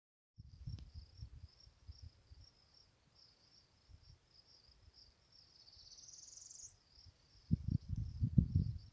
Northern Parula
Price River Valley, SE of Price, Carbon Co.